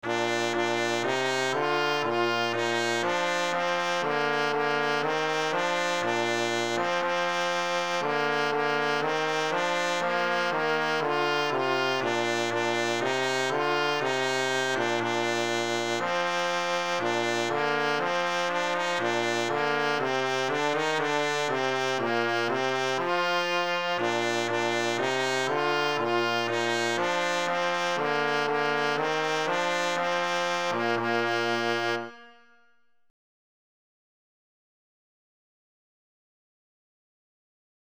Arrangements